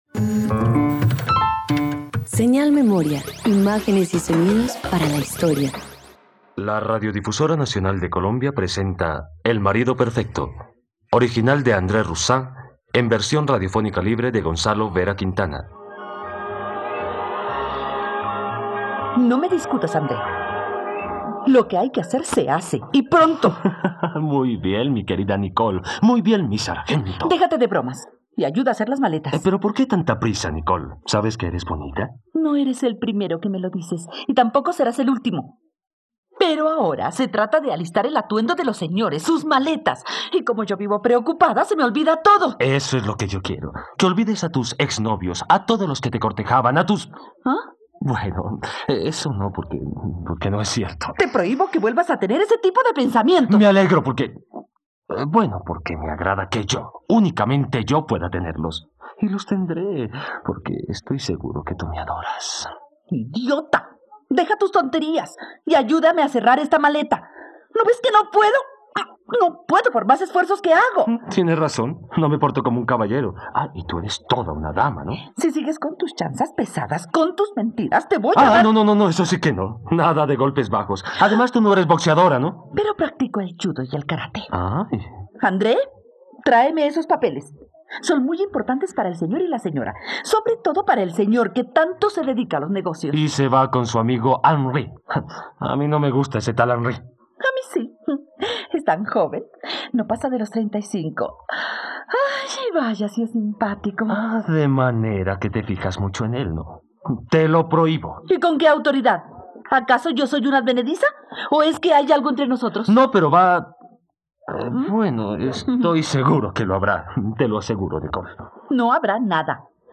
El marido perfecto - Radioteatro dominical | RTVCPlay